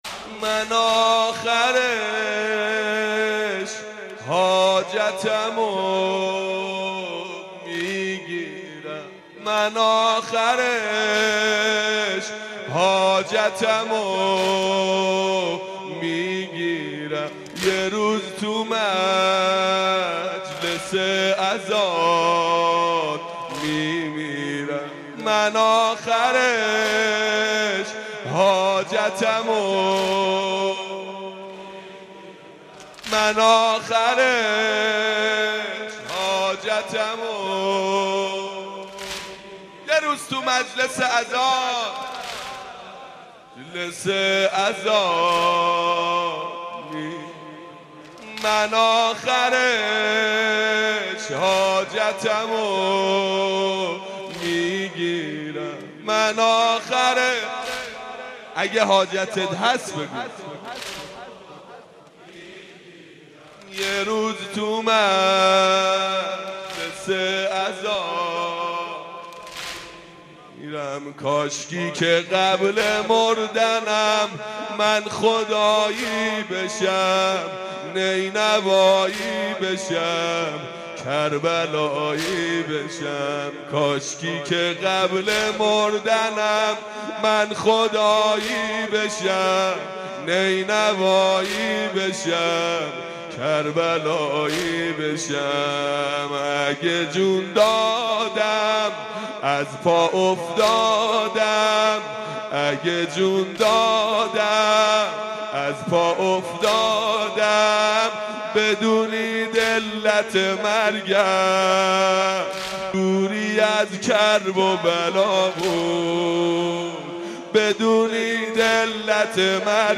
مداحی شنیدنی